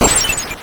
IcicleCrash.wav